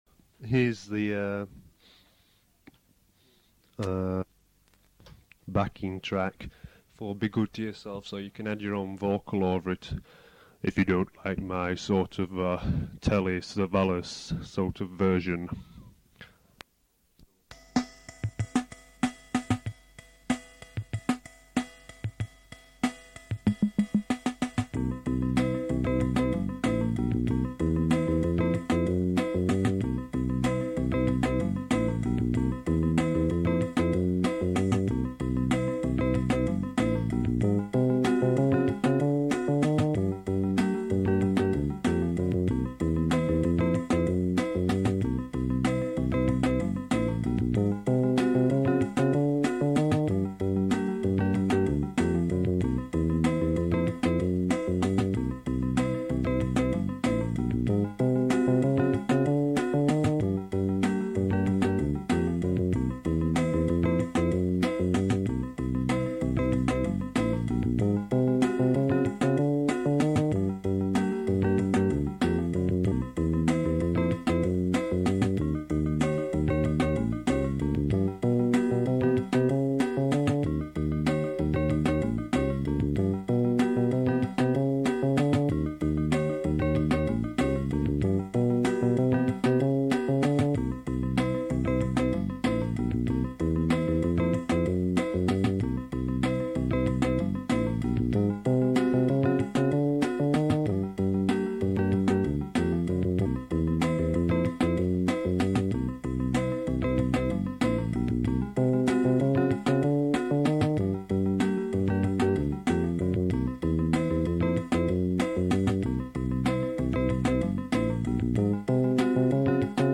be_good_to_yourself_backing.mp3